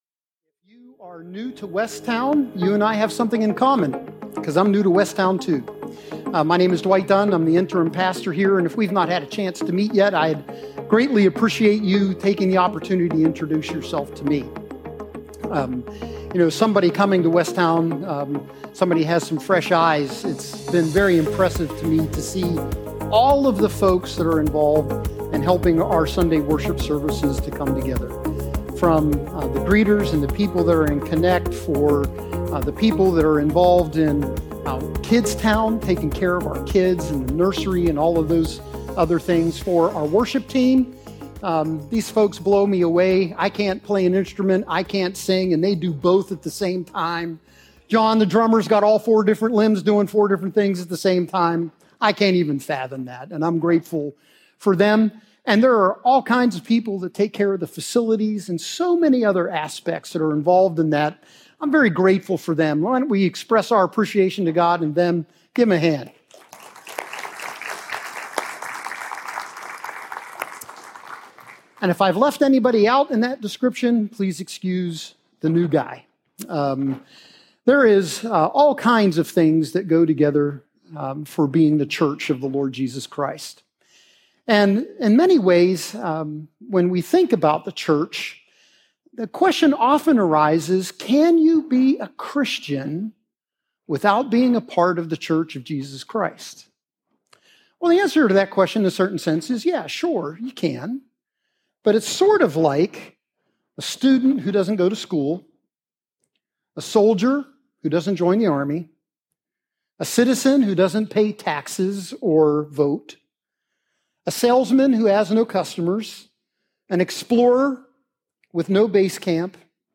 A message series in 1 Thessalonians, that teaches us about knowing and following Jesus together. A book full of encouragement to follow Jesus and live on mission for Him as individuals and as a body of believers as we await His return!This week, we will look at Paul’s encouragement to the church in chapter 1.